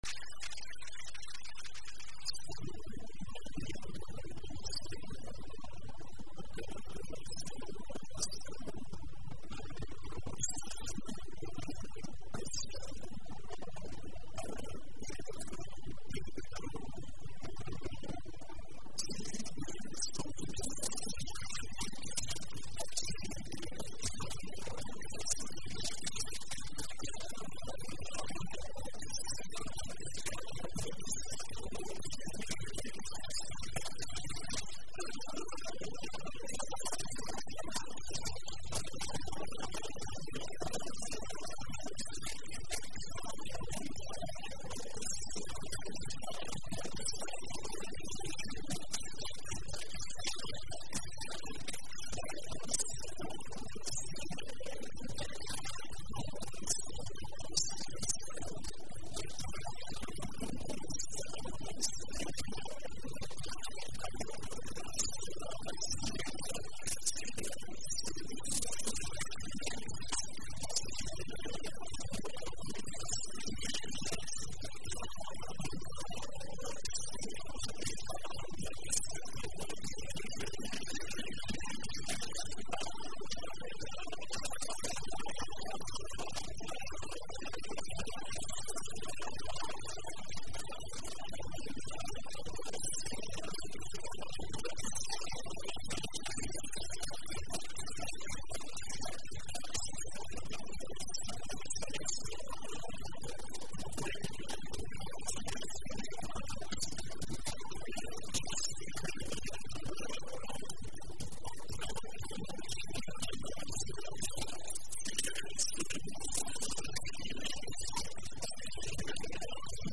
Жанр: Retro